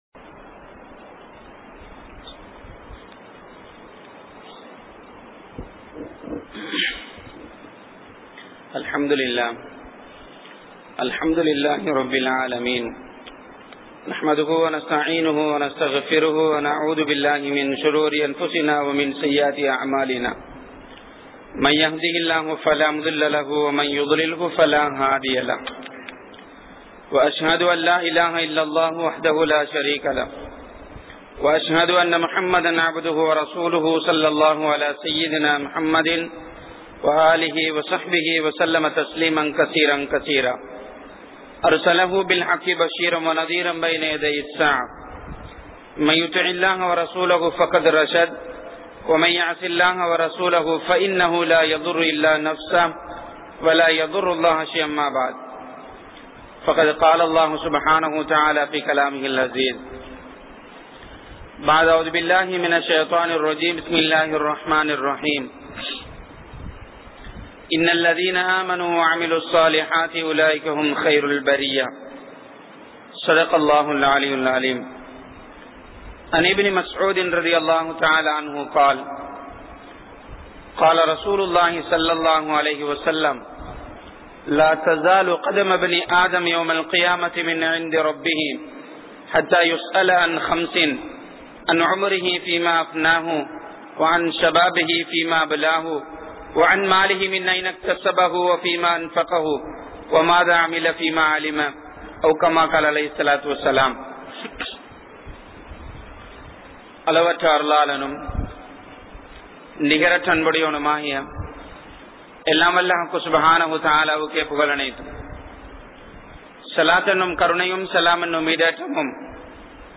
Qiyamath Naalil Manithanin Nilamai | Audio Bayans | All Ceylon Muslim Youth Community | Addalaichenai
Kanampittya Masjithun Noor Jumua Masjith